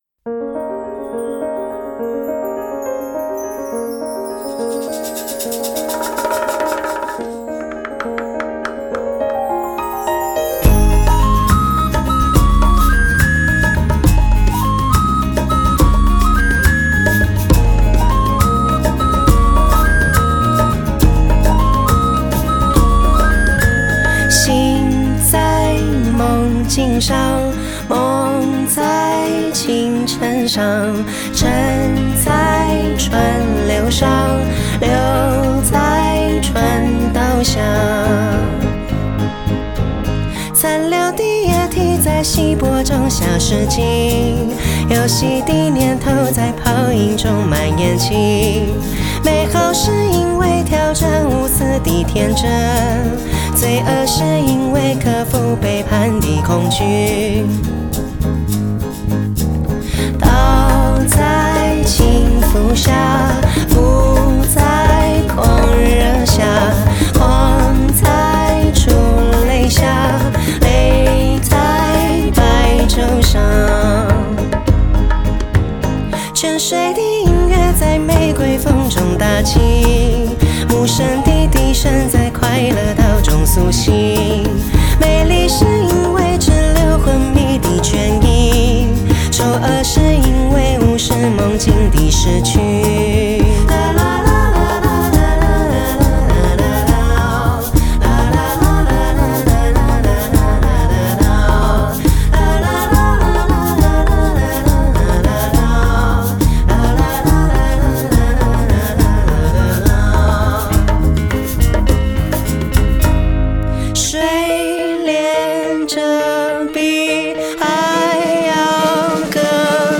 音乐类型：流行（Pop）